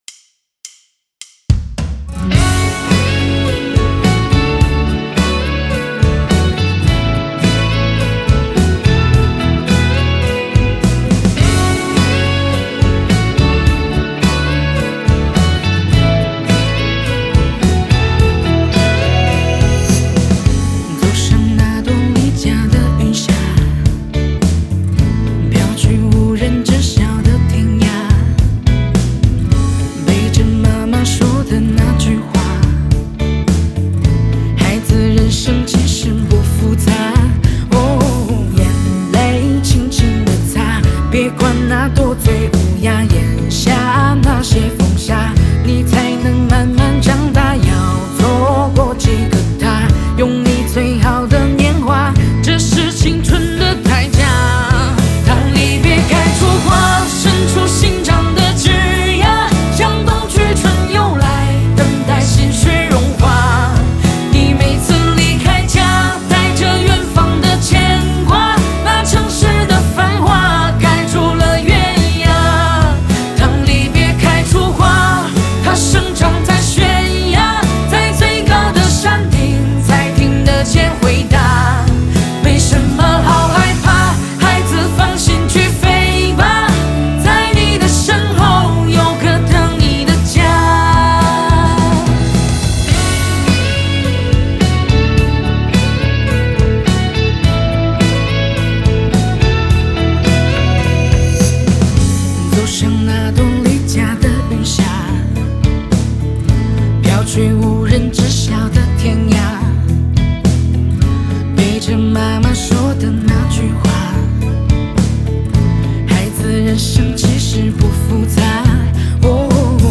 吉他/贝斯Guitar&bass